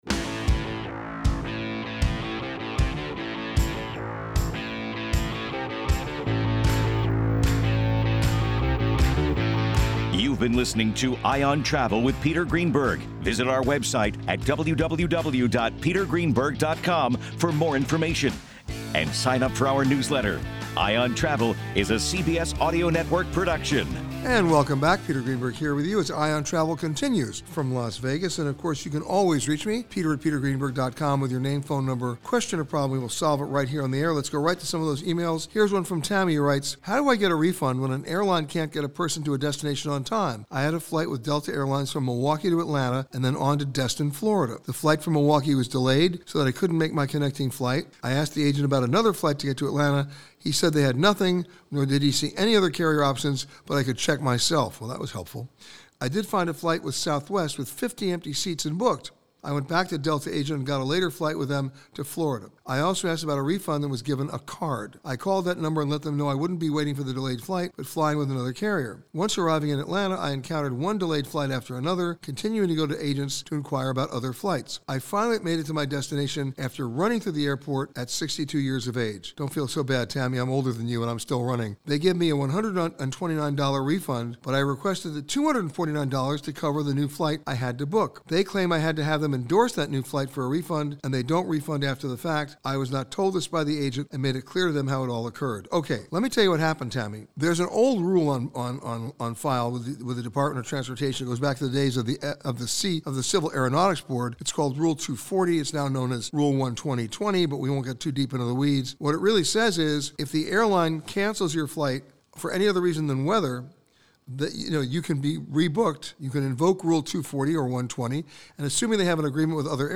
This week, Peter answered your questions from the Bellagio in Las Vegas, Nevada. Peter answers your questions on airline refunds, travel insurance, credit cards, and more.